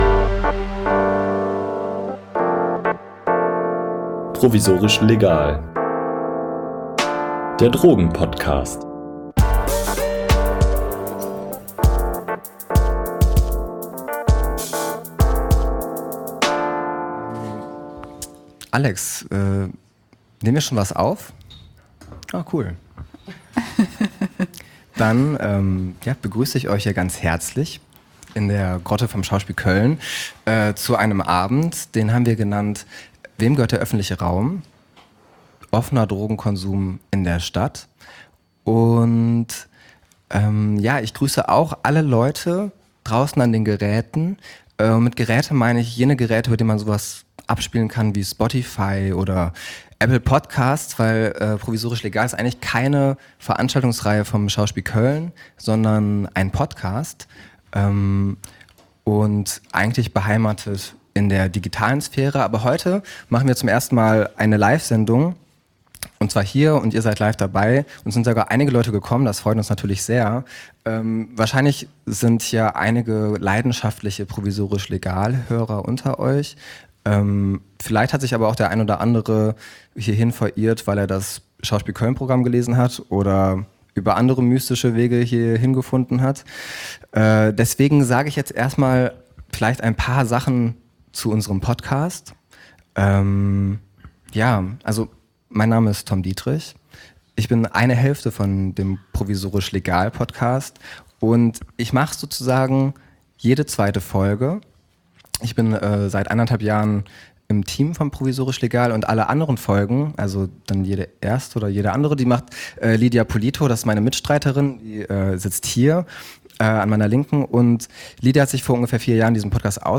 Die Aufnahme fand mit Publikum im Schauspiel Mülheim statt.